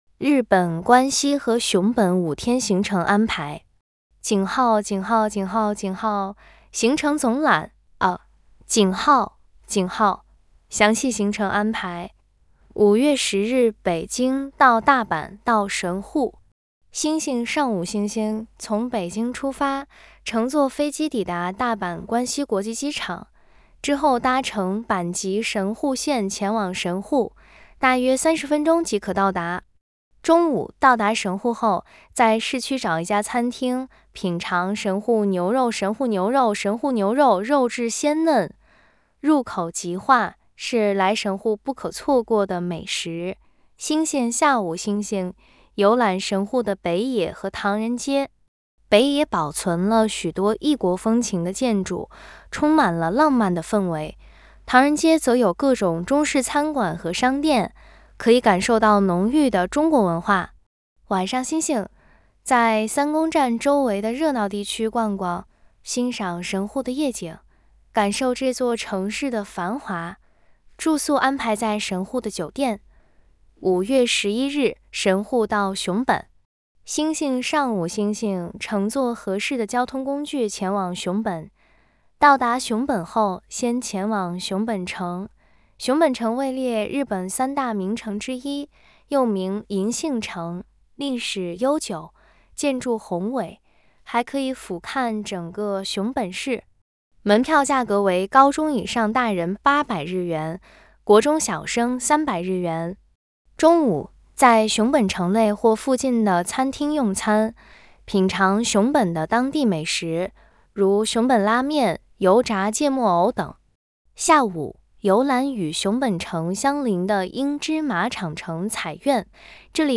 我接入了语音合成的工具，让它给我把文字攻略转成语音版本，agent很快就输出了语音版本：
这个声音听着很像豆包app默认的女声声音，虽然把符号那些也连带着一起读了，但这个功能是方便好用的。